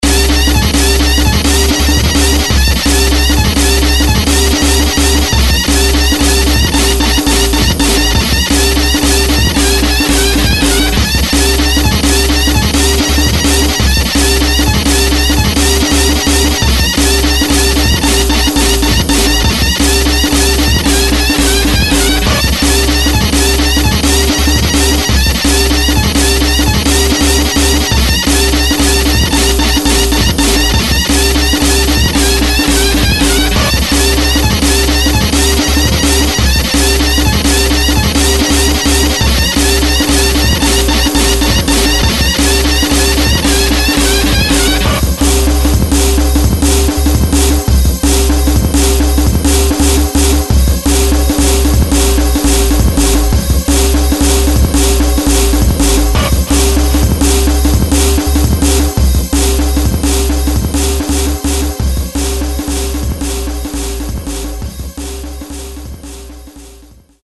ПИЗДАТЫЙ ДРАМ!